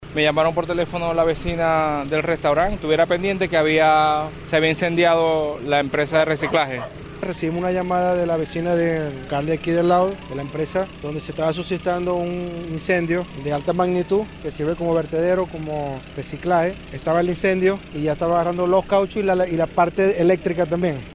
Trabajadores del sector industrial entregaron más detalles de los primeros minutos de la emergencia, relatando que hubo diversas explosiones por el material inflamable que había en el lugar.